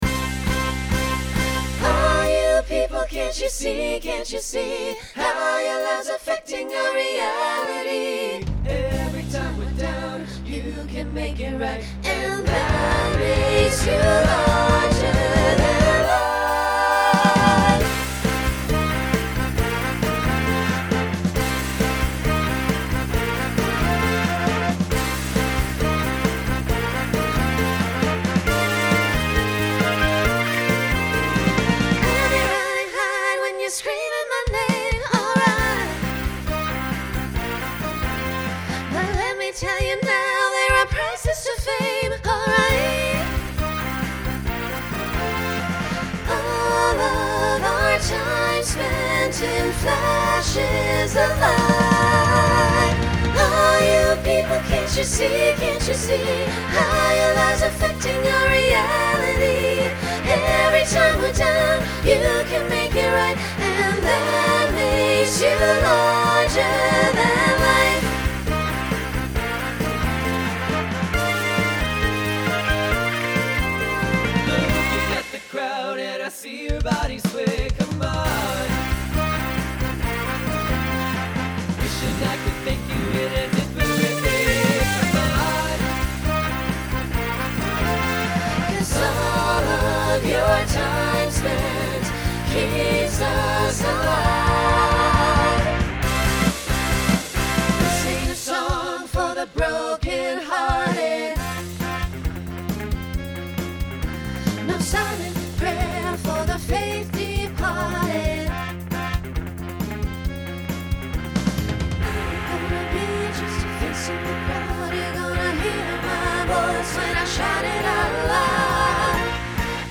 Uses a quartet to facilitate costume change.
Pop/Dance , Rock
Voicing Mixed